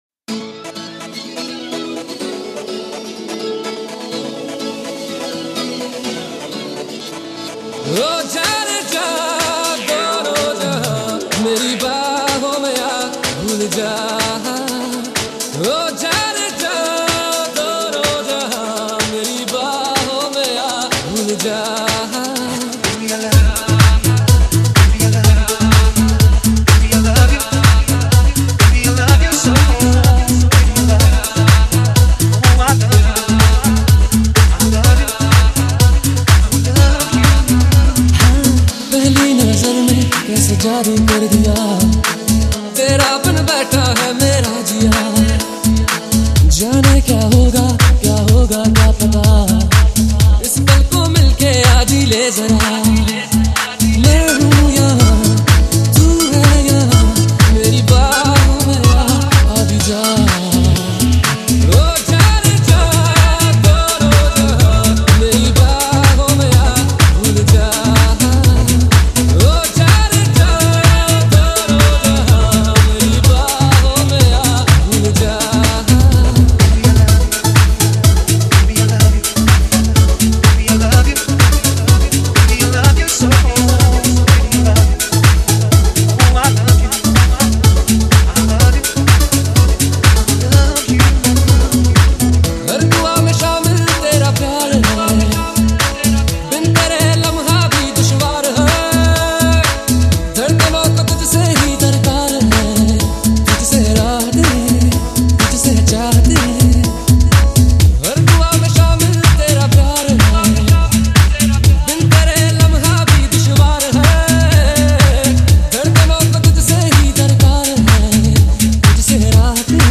Bollywood MP3 Songs